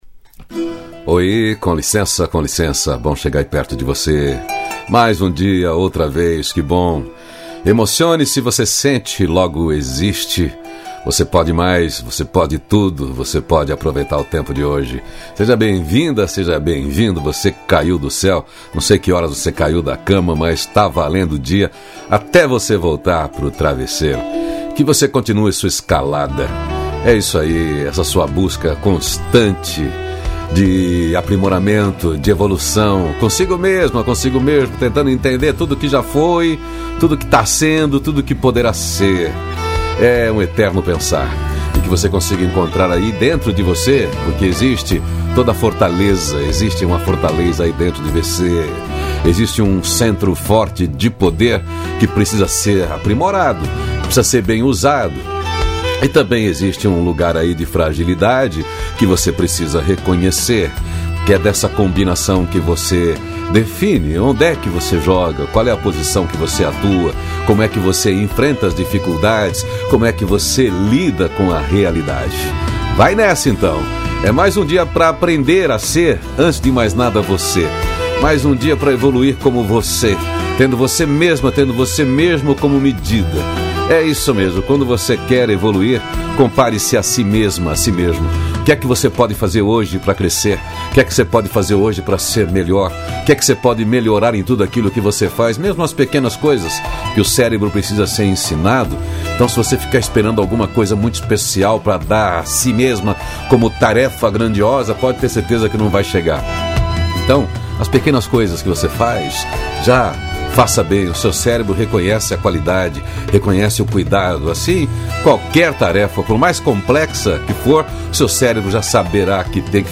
CRÉDITOS: Produção e Edição: Onion Mídia Trilha Sonora: “Between the Shadows” Loreena Mckennitt